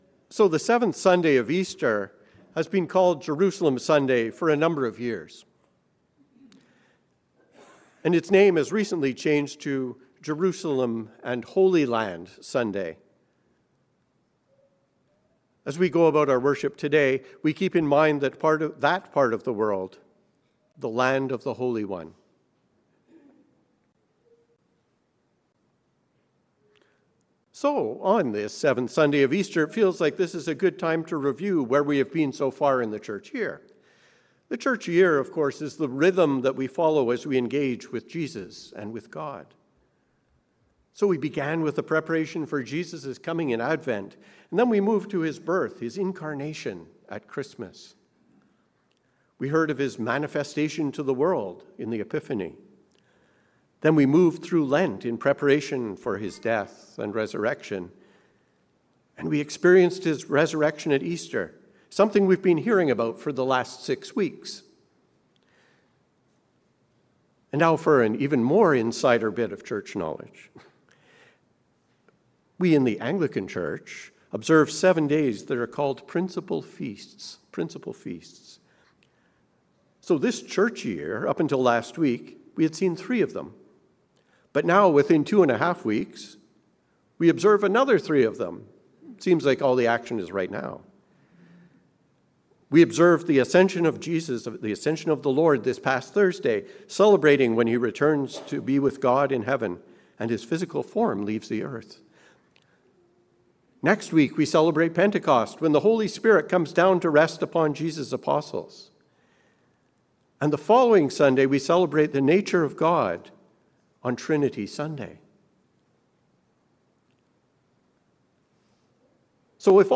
From learning to doing. A sermon on John 17:6-19